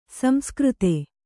♪ samskřte